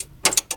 relay1.wav